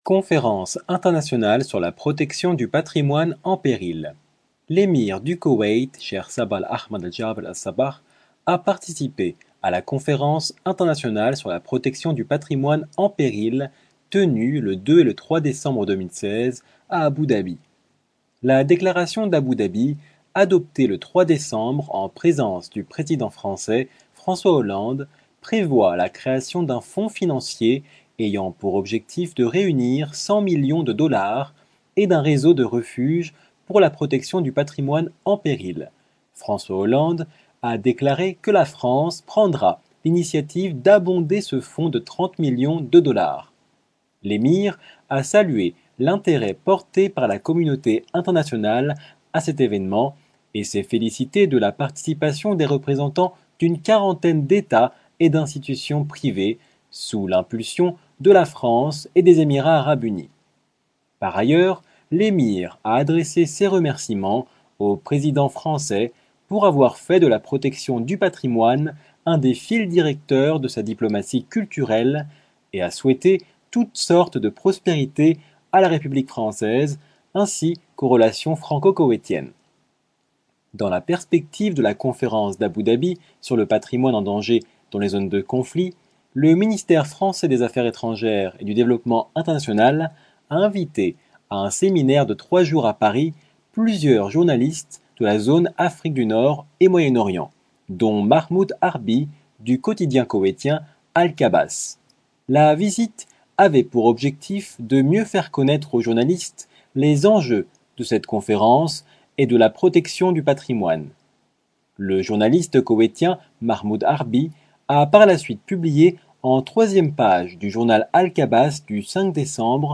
Discours de François Hollande lors de la Conférence d'Abou Dabi